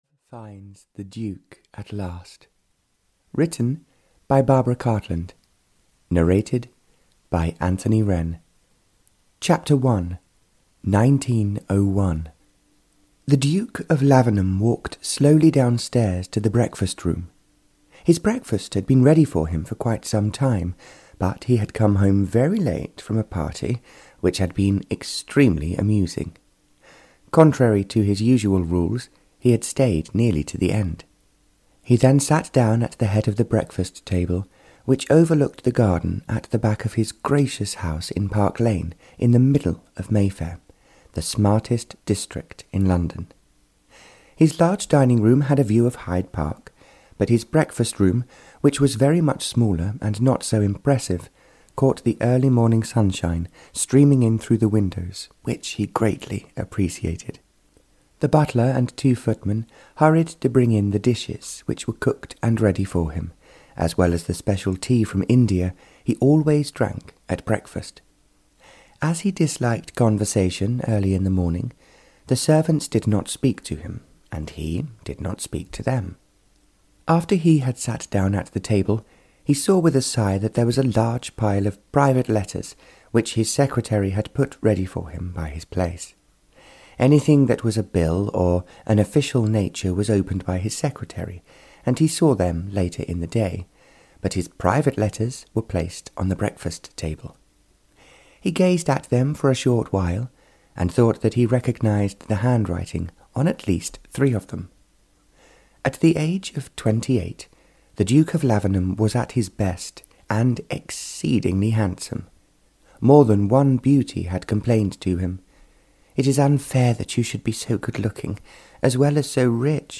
Audio knihaLove Finds The Duke at Last (Barbara Cartland's Pink Collection 160) (EN)
Ukázka z knihy